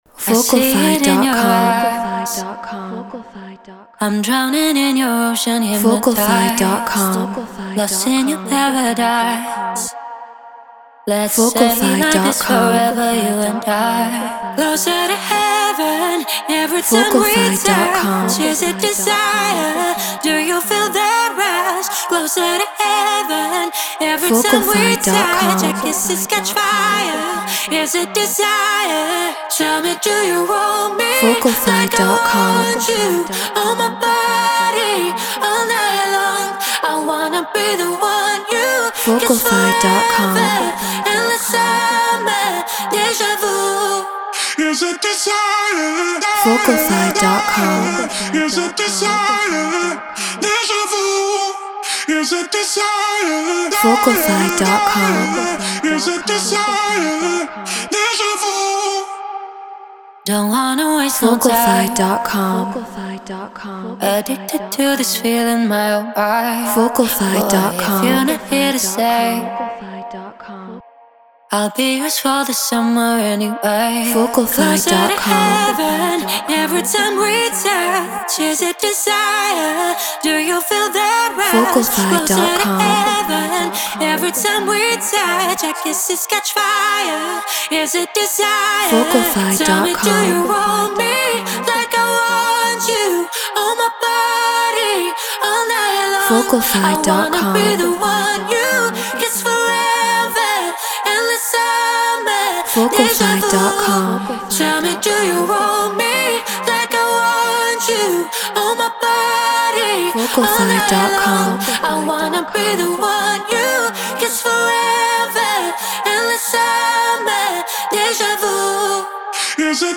Non-Exclusive Vocal.
House 128 BPM Abmaj
Treated Room